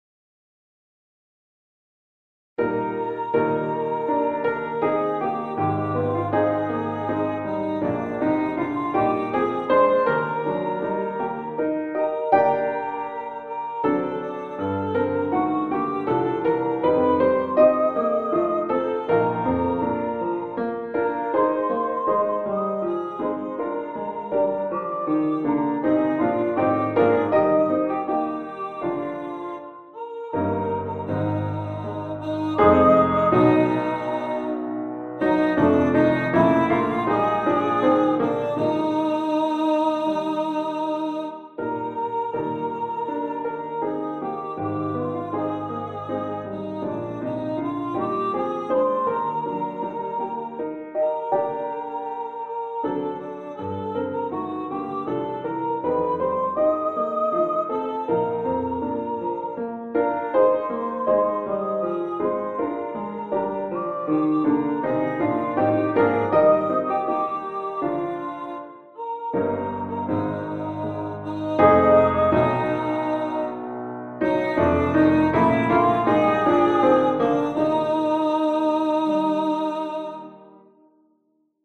Voices: Solo Voice Instrumentation: Piano
NotePerformer 5.1 mp3 Download/Play Audio